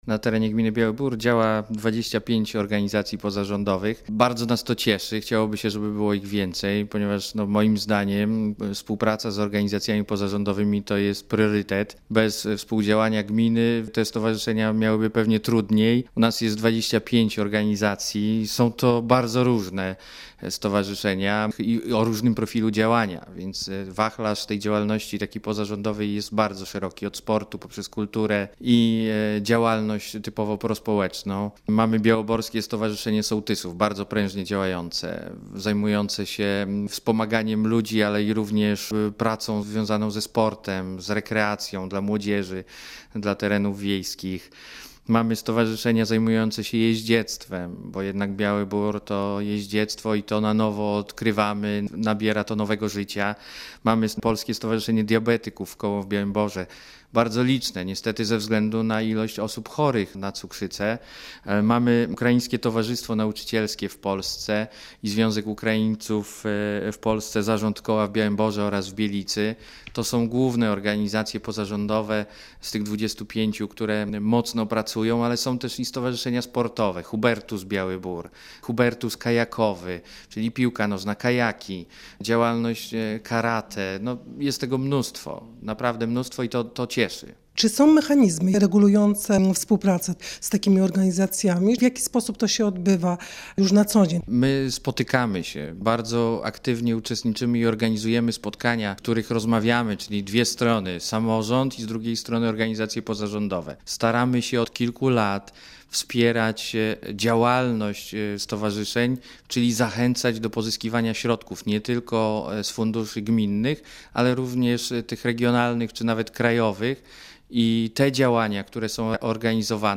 Nagranie - Radio Koszalin w rozmowie z burmistrzem Białego Boru Pawłem Mikołajewskim (red.